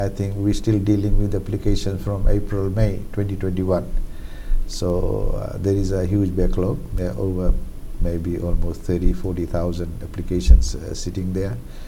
Speaking on the FBC TV’s ‘Saqamoli Matters’ Show, Singh emphasized that applications dating back to mid-2021 are still in the pipeline for processing.